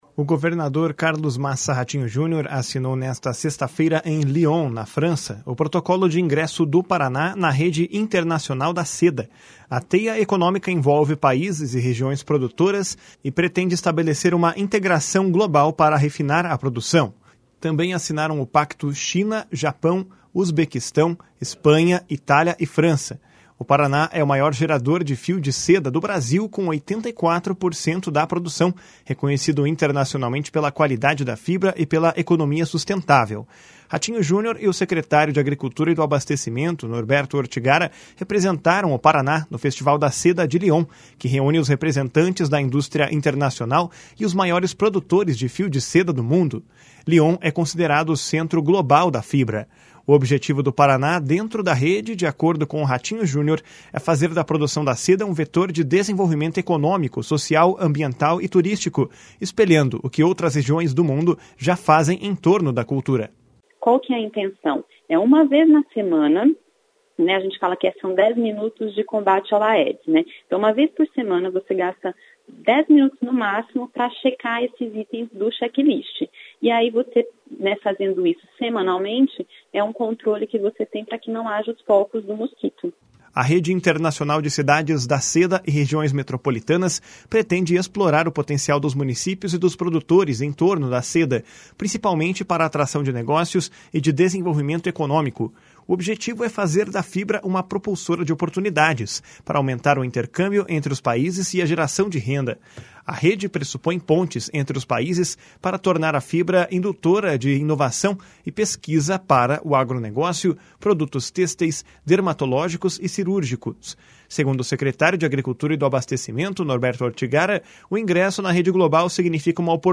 // SONORA RATINHO JUNIOR //
// SONORA NORBERTO ORTIGARA //